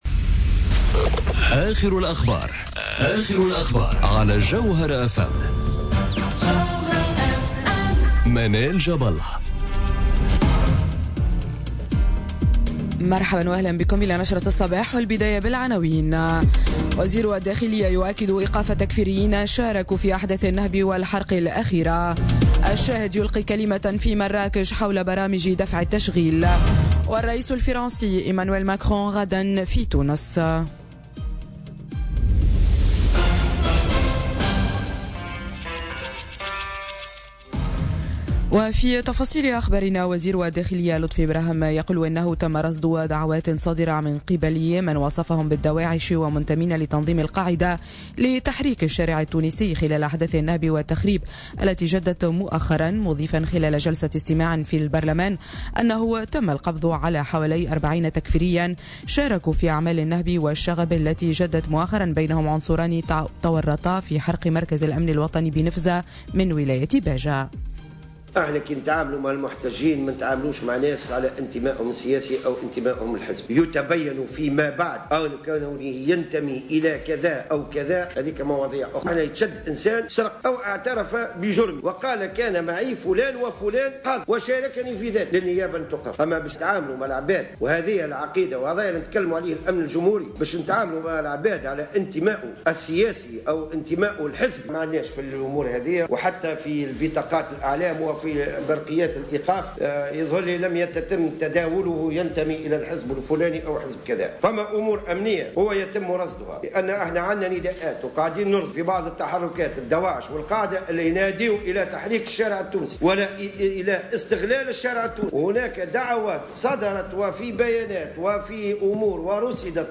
نشرة أخبار السابعة صباحا ليوم الثلاثاء 30 جانفي 2018